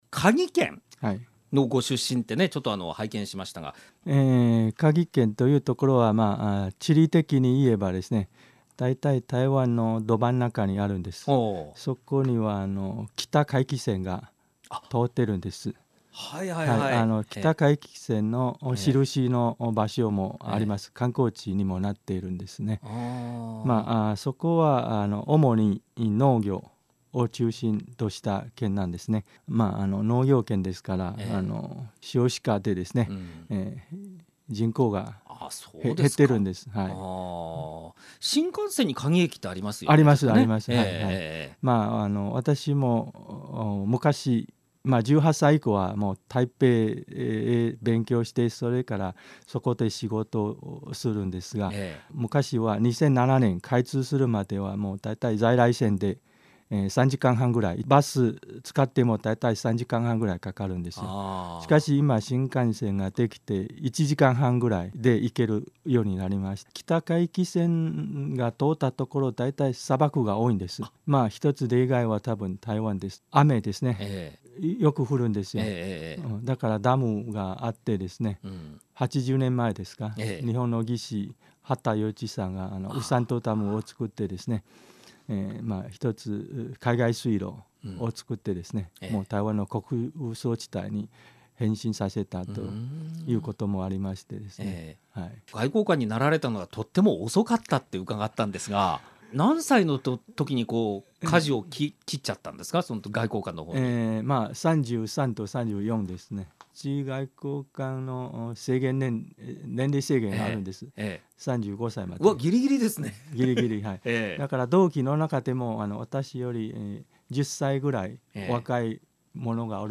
台北駐日経済文化代表処　那覇分処長　蘇啓誠さん
まずは、蘇さんの日本語力の素晴らしさをお聞き下さい。